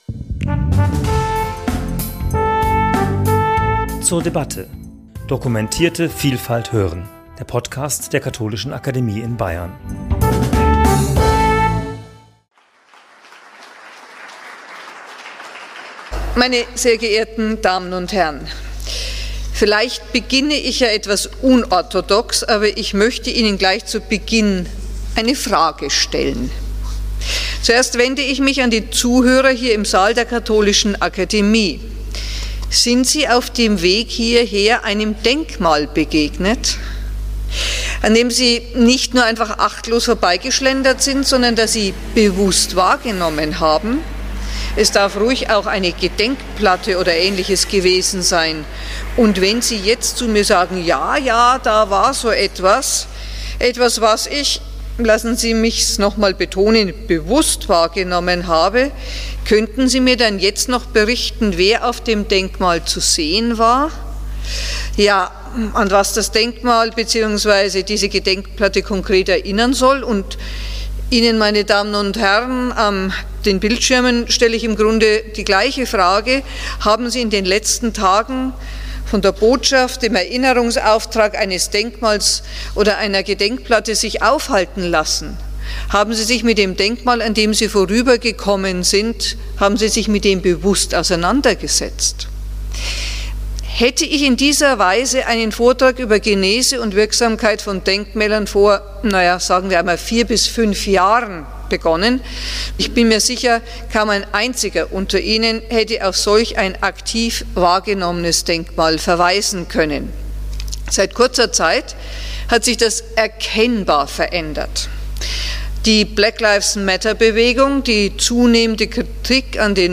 in der Katholischen Akademie in Bayern